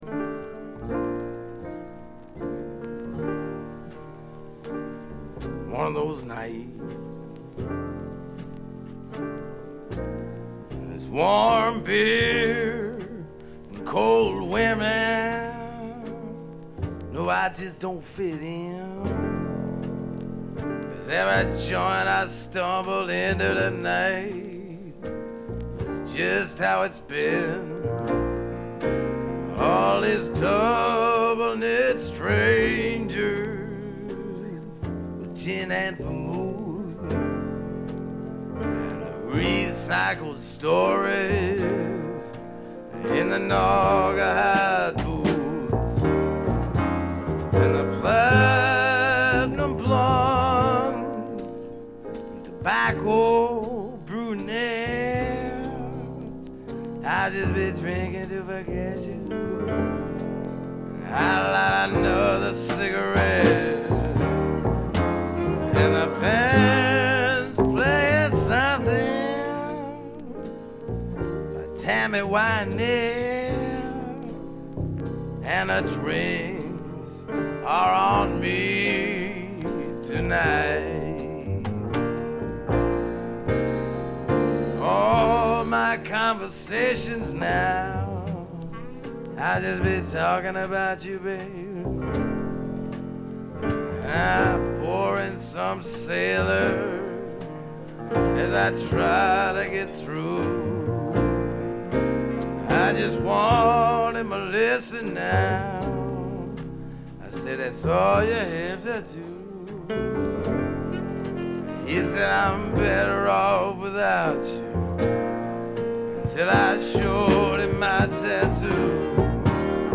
部分試聴ですがわずかなノイズ感のみ。
70年代シンガー・ソングライターの時代を代表するライヴアルバムでもあります。
試聴曲は現品からの取り込み音源です。